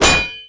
wrenchimpact05.wav